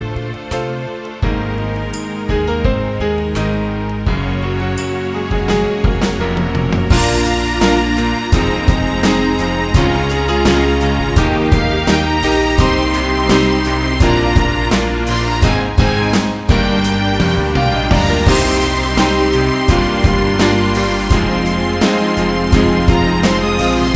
No Guitars Pop (2000s) 3:45 Buy £1.50